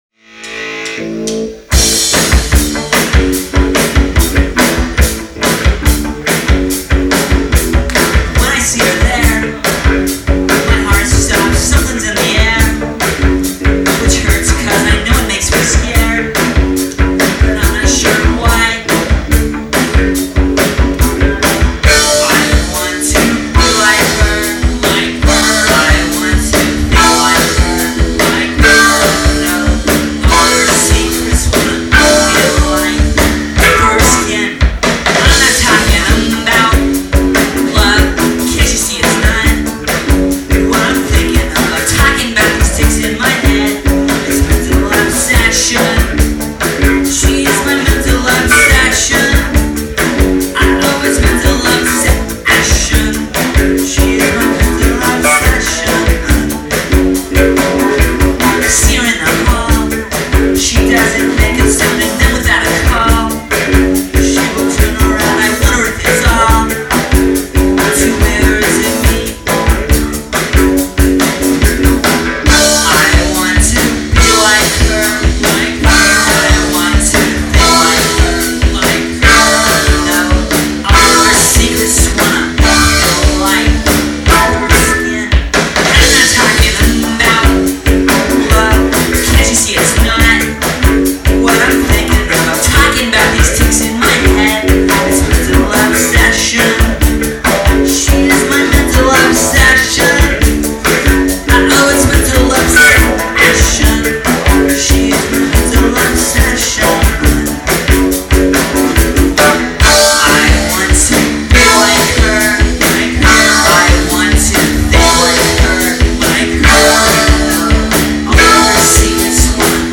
in front of a very small audience in 1995.